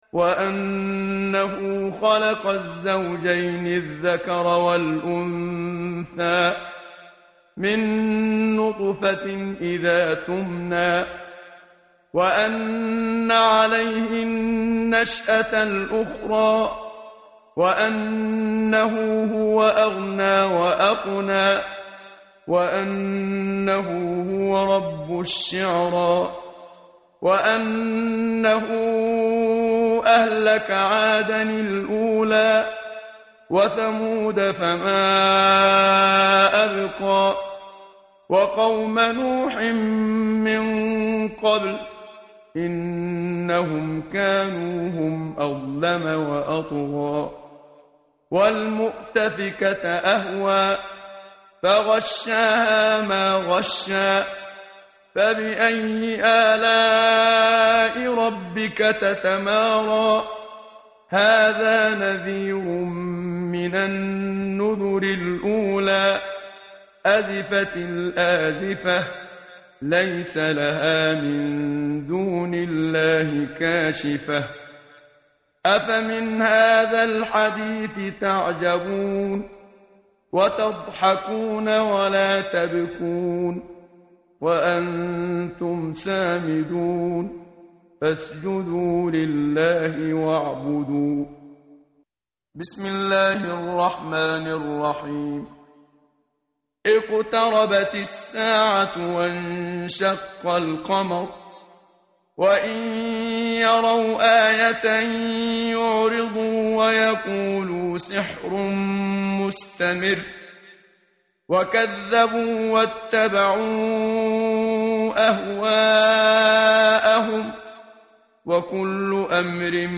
tartil_menshavi_page_528.mp3